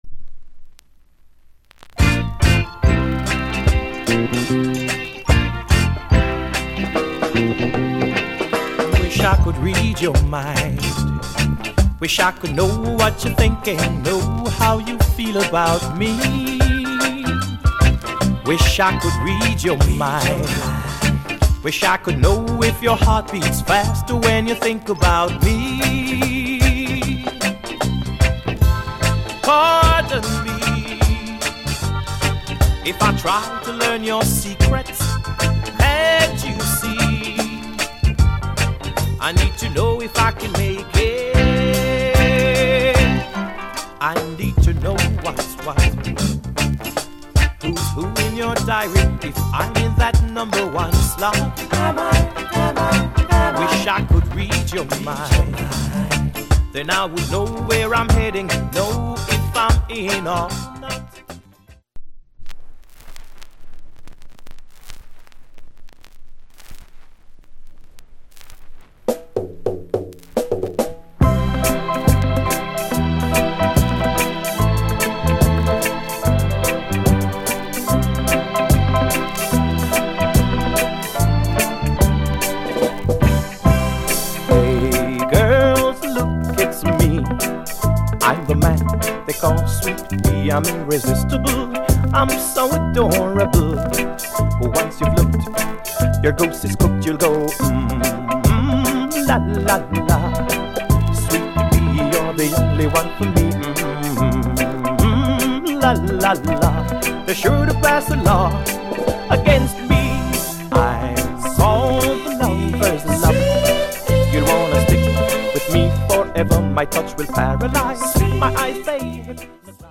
Genre Reggae70sMid / Male Vocal Group Vocal
* やさしくてソウルフルな歌モノが好きな人にオススメ。